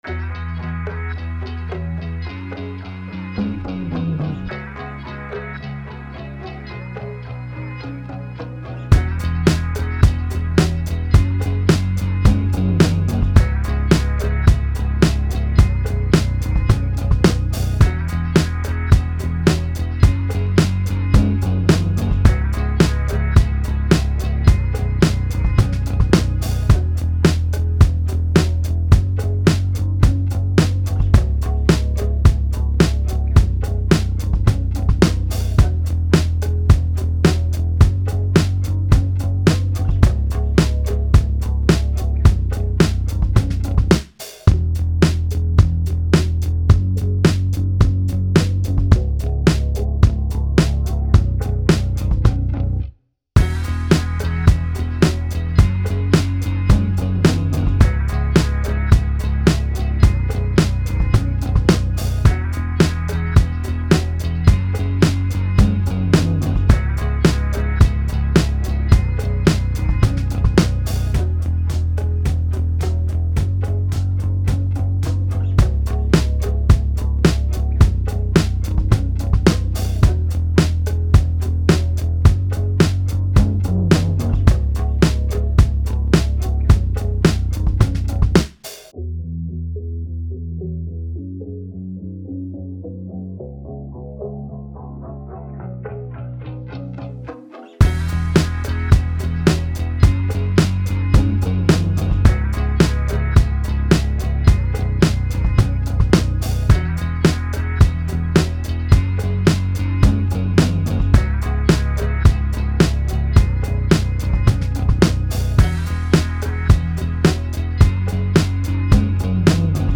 Indie Pop, Indie Rock, Indie
c#Minor